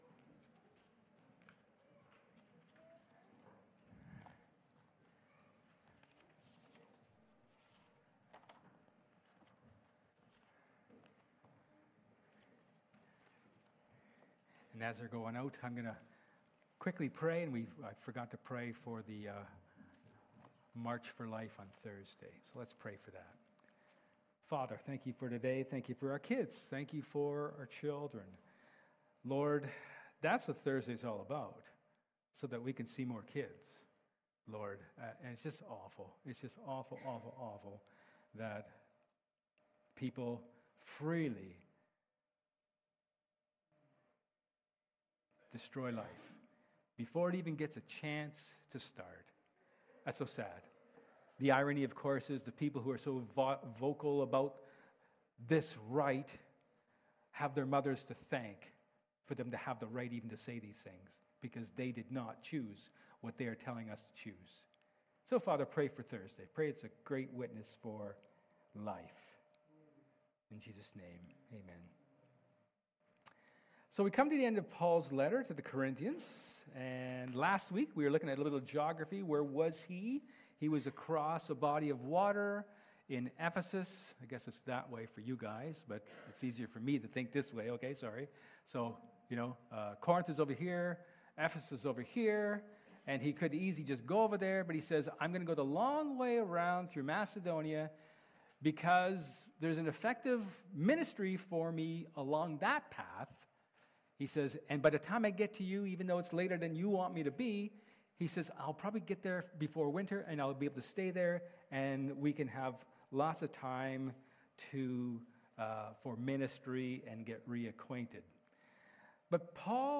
Service Type: Sermon
May-4th-sermon.mp3